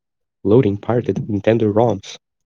loading-pirated-nintendo.wav